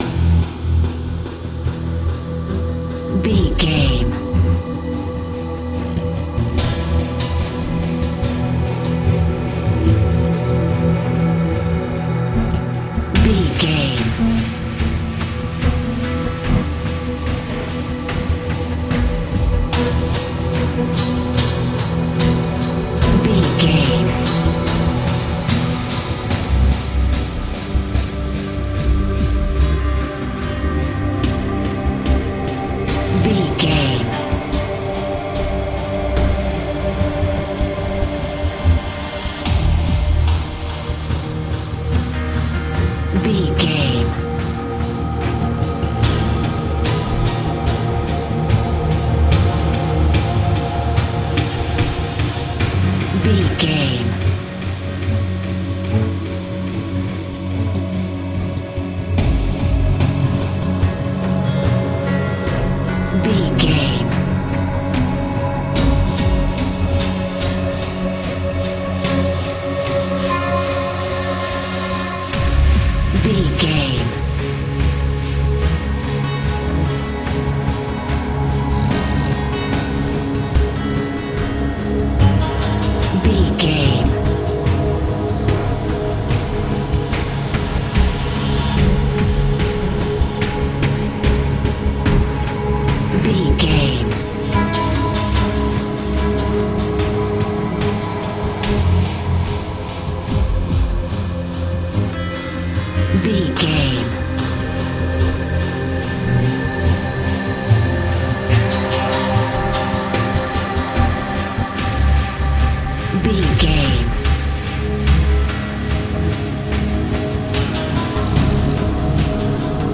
Thriller
Aeolian/Minor
strings
drums
cello
violin
percussion